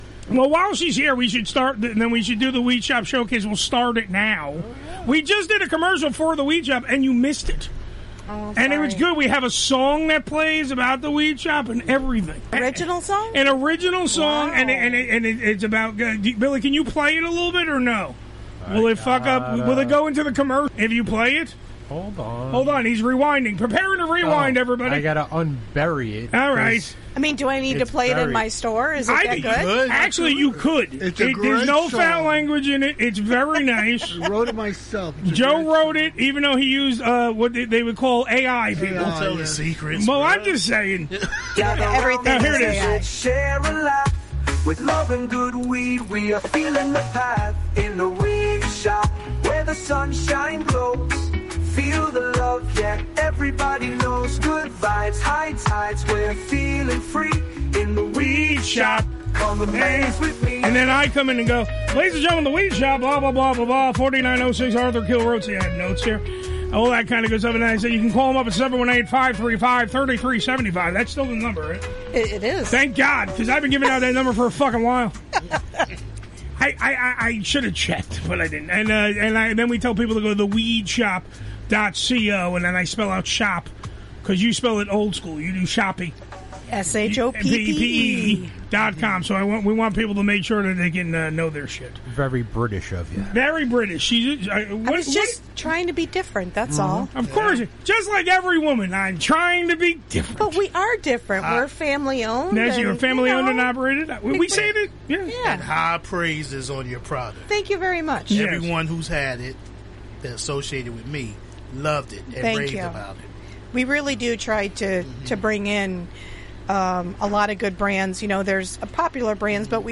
Note: This episode also features a candid, often humorous, discussion about PowerSlap, the controversial combat sport involving open-hand striking, and its potential for head trauma